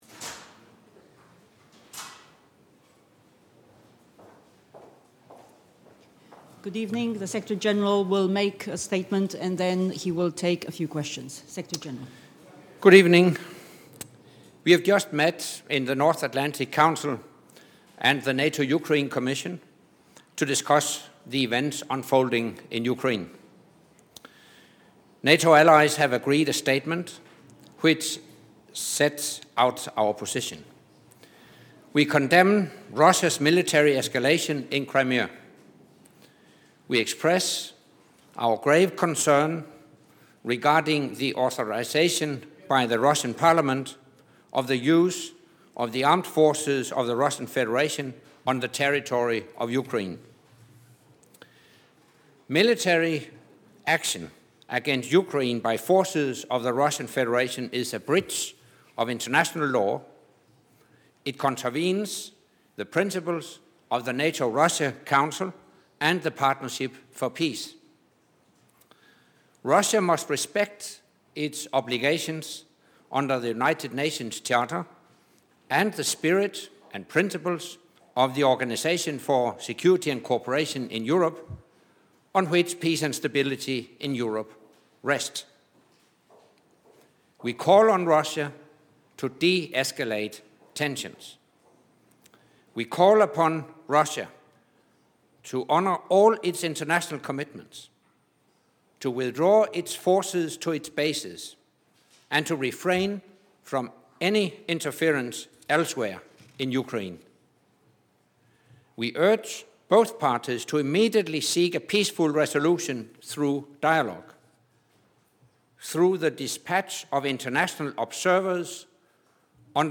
Заявление Генерального секретаря для прессы на пресс-конференции в штаб-квартире НАТО в Брюсселе после заседания Комиссии НАТО–Украина
ENGLISH: Press conference by NATO Secretary General Anders Fogh Rasmussen following the meeting of the NATO-Ukraine Commission 02.03.2014 | download mp3 FRENCH: Press conference by NATO Secretary General Anders Fogh Rasmussen following the meeting of the NATO-Ukraine Commission 02.03.2014 | download mp3